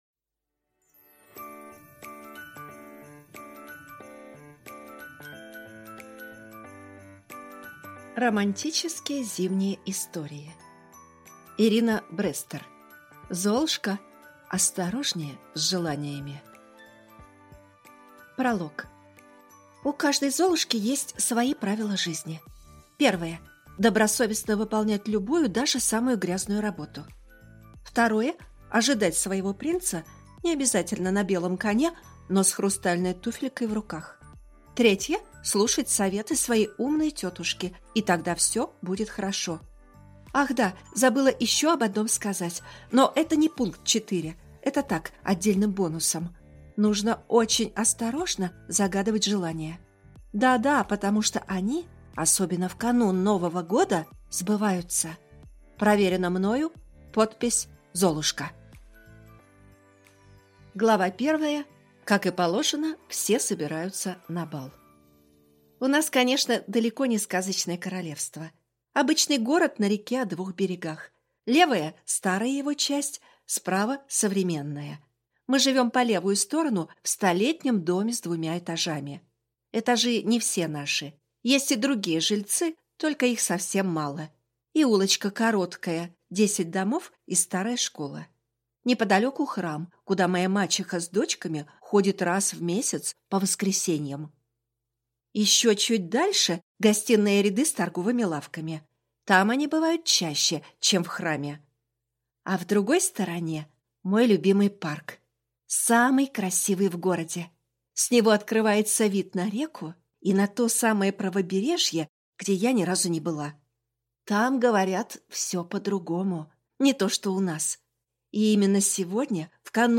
Аудиокнига Золушка, осторожнее с желаниями!